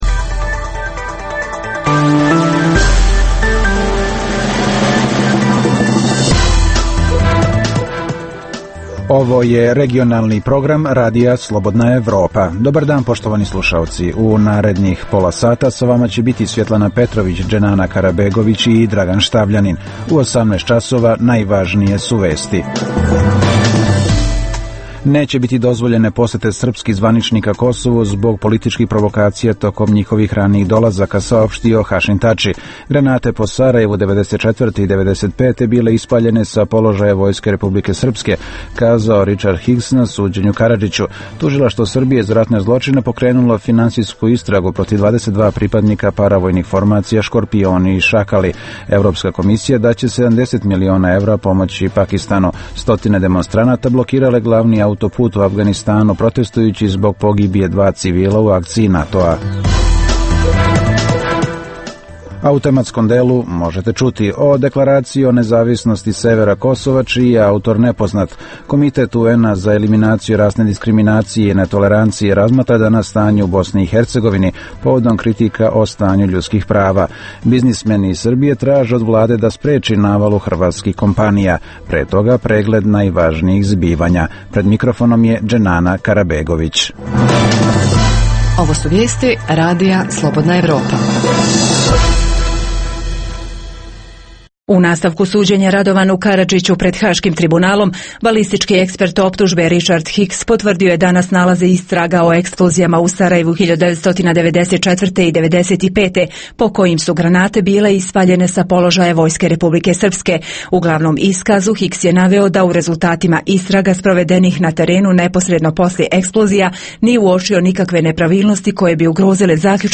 - Hrvatska među 30 zemalja najboljih za život. - Intervju